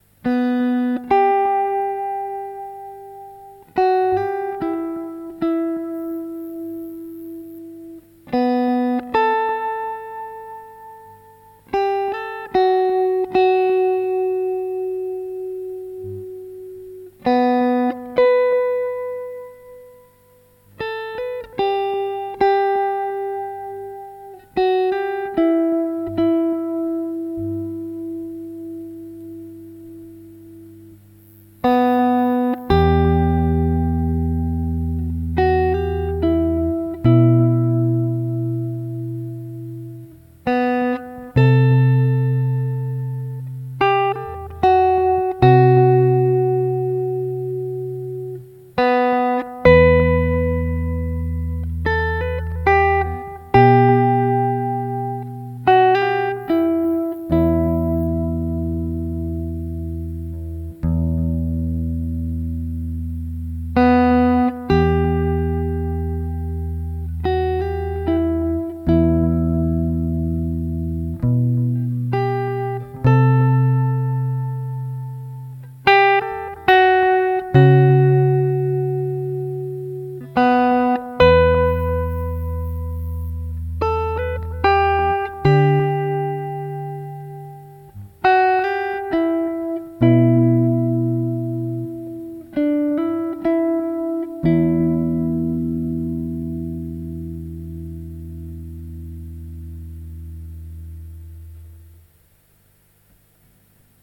Musik
Solo-Gitarre